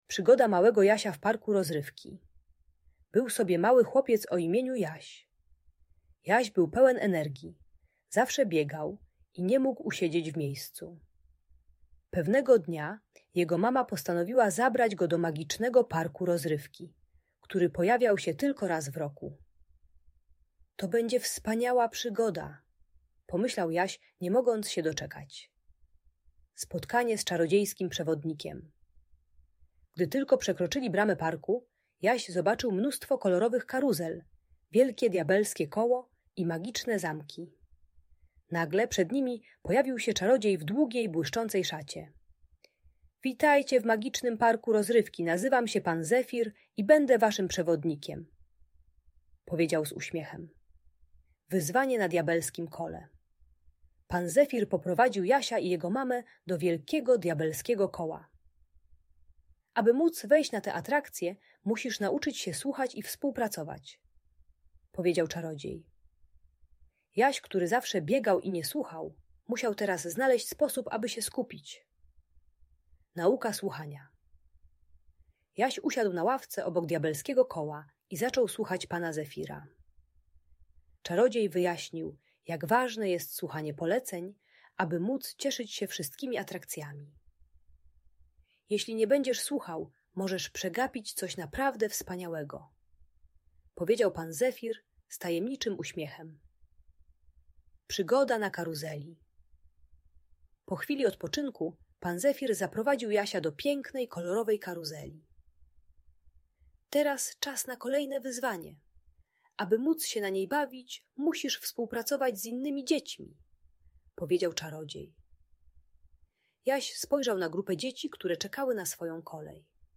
Przygoda małego Jasia w parku rozrywki - story - Audiobajka